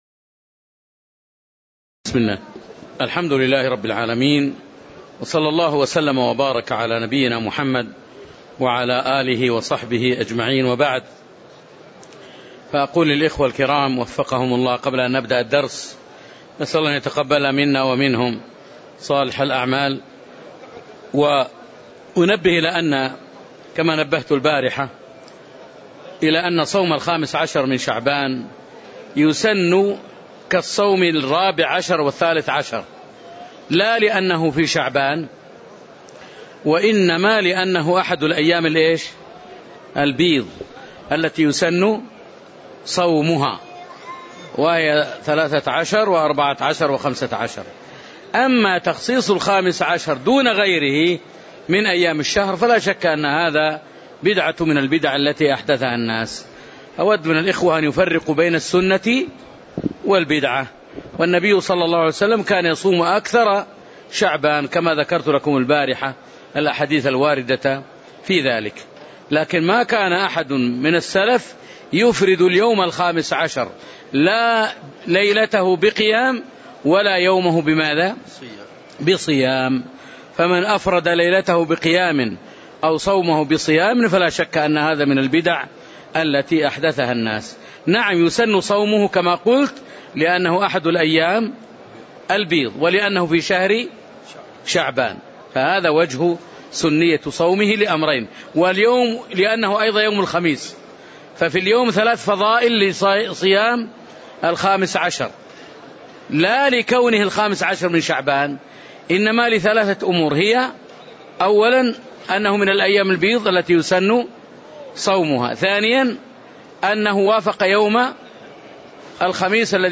تاريخ النشر ١٥ شعبان ١٤٣٨ هـ المكان: المسجد النبوي الشيخ